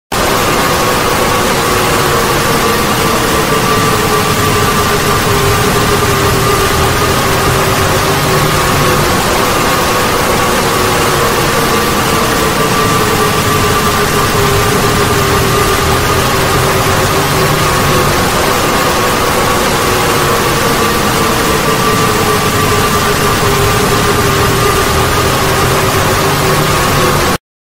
Шумы сигнализирующие о приближении Амбуш
Амбуш_шум_1.mp3.mp3